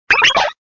Fichier:Cri 0417 DP.ogg
Cri de Pachirisu dans Pokémon Diamant et Perle.